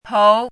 抔字怎么读, 抔字的拼音, 抔字的组词, 抔字的含义 - 天天识字
póu
pou2.mp3